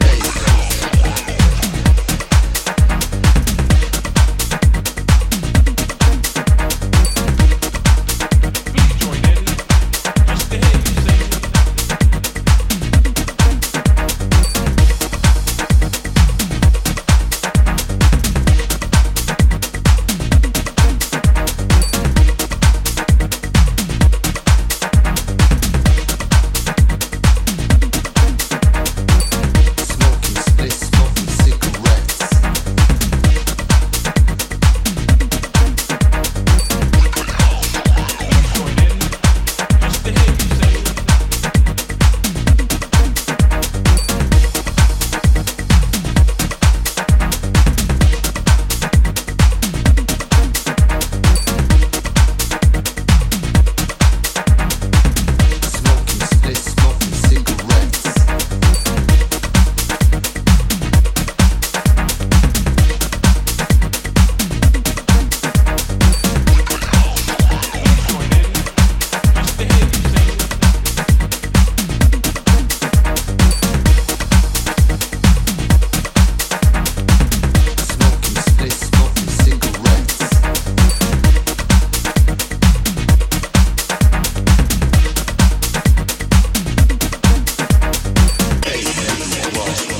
ラテン・パーカッシヴなパーティー着火剤
陽性なパーティーフレイヴァがこれからの季節にもぴったりな秀逸ハウスを展開